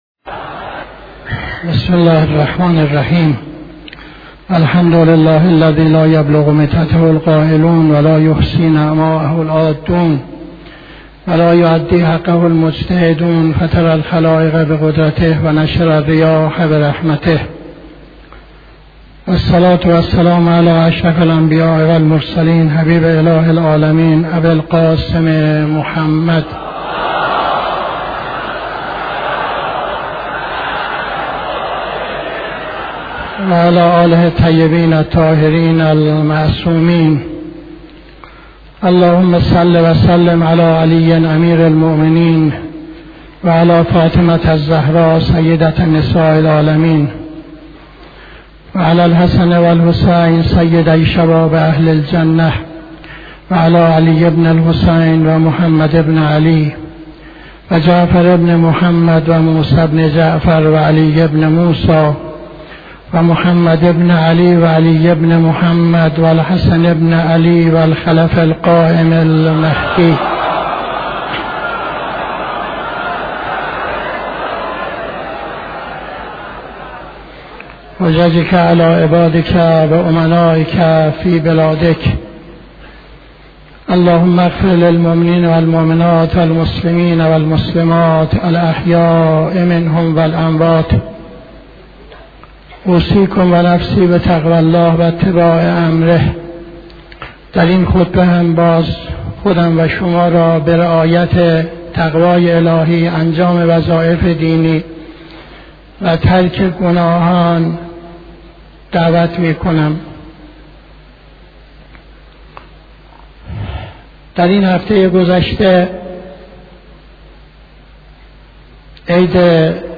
خطبه دوم نماز جمعه 12-01-79